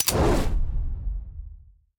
select-flame-3.ogg